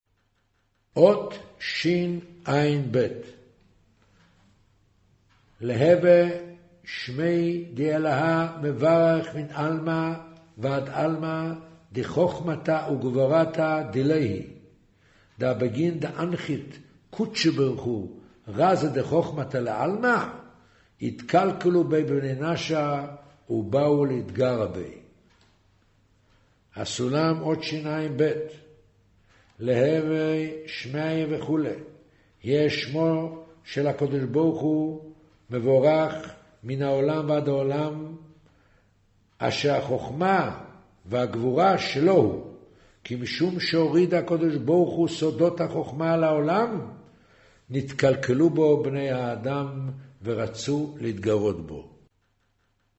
קריינות זהר, פרשת נח, מאמר ויאמר ה' הן עם אחד